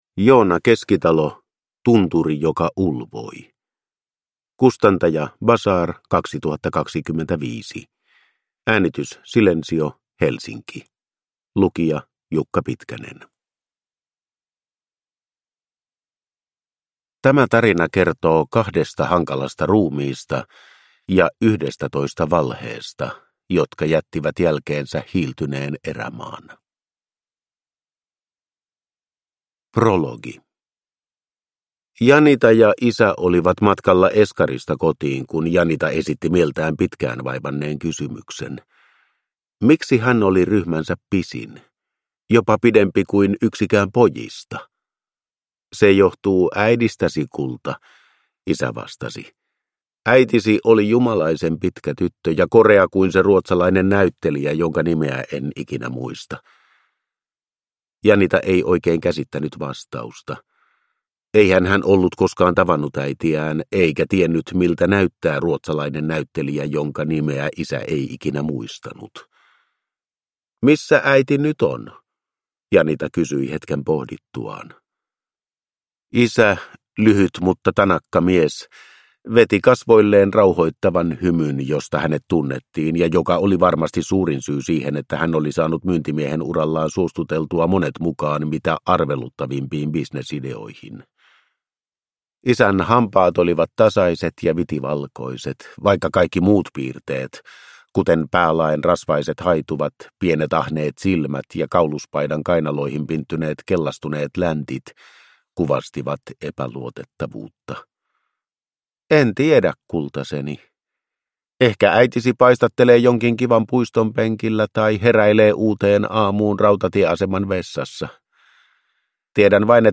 Tunturi, joka ulvoi (ljudbok) av Joona Keskitalo